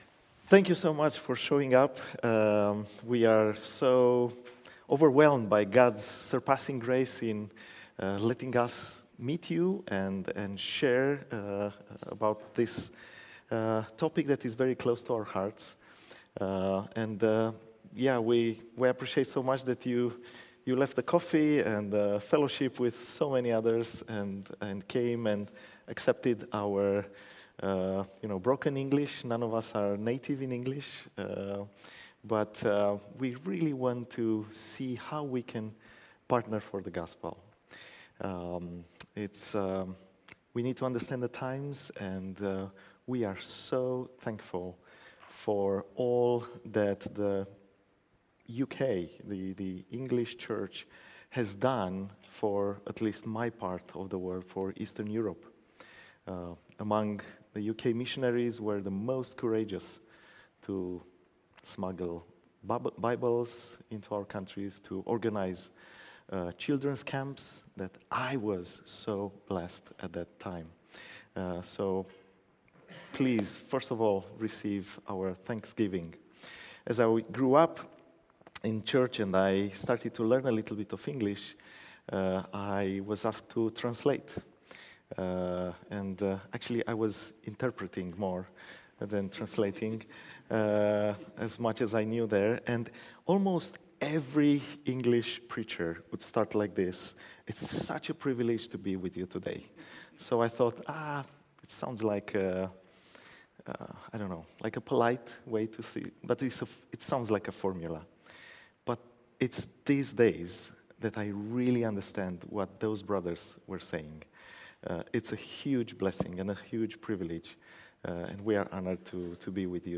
Series: Leaders' Conference 2025